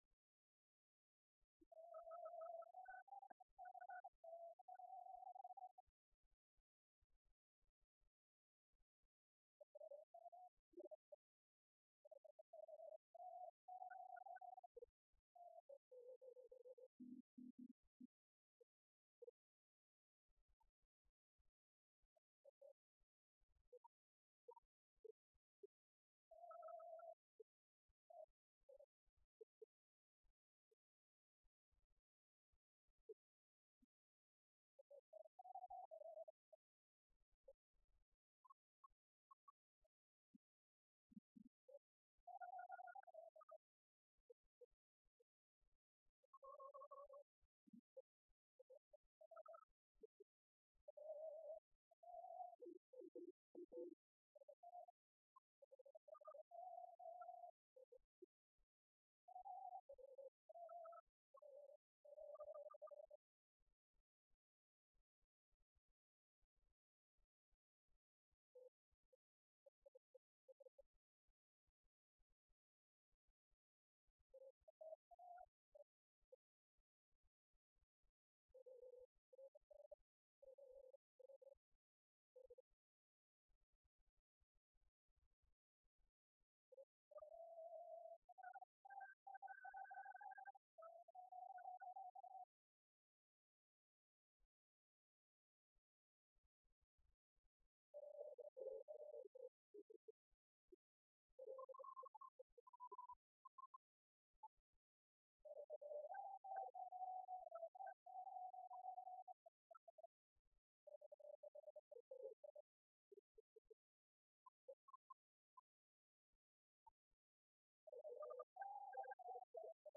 Concert de Noël à La Loge
Choeur
Pièce musicale inédite